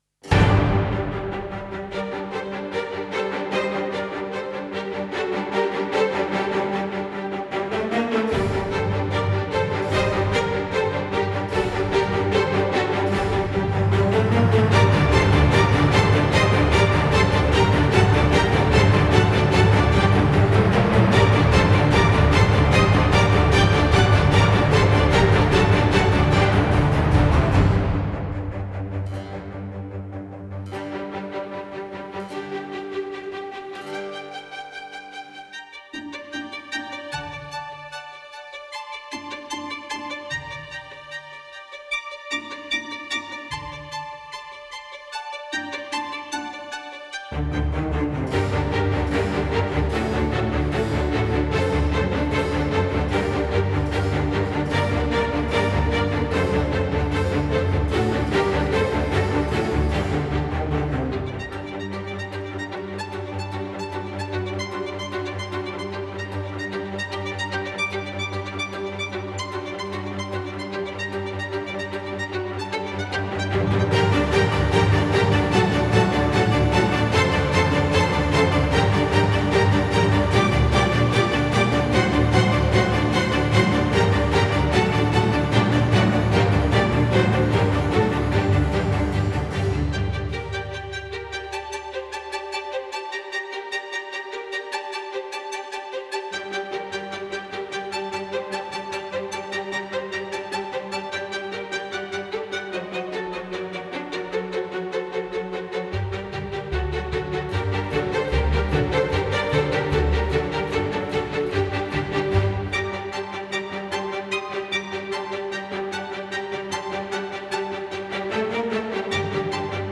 Tempo 100 BPM